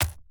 Sfx_creature_rockpuncher_walk_slow_left_legs_01.ogg